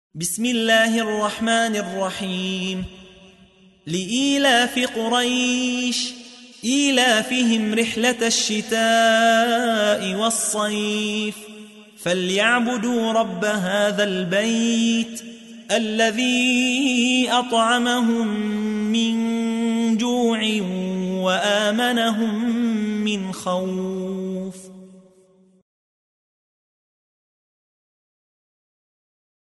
تحميل : 106. سورة قريش / القارئ يحيى حوا / القرآن الكريم / موقع يا حسين